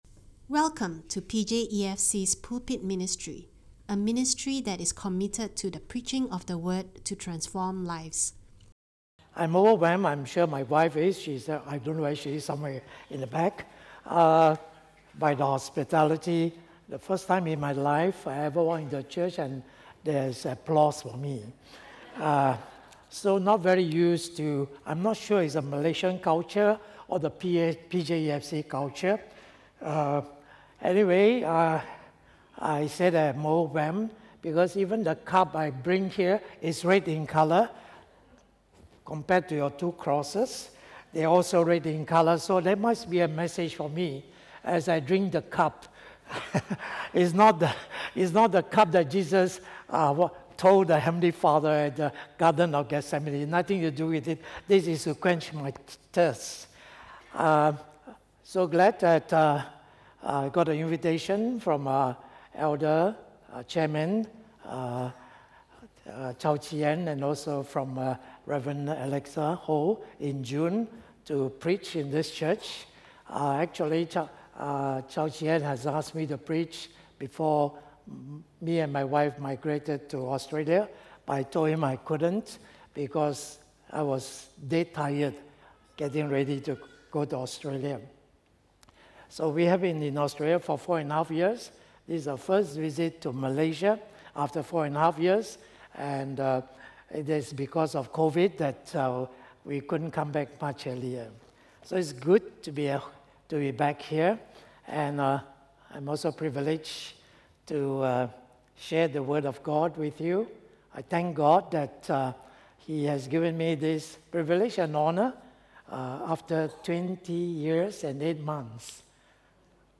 This is a stand-alone sermon.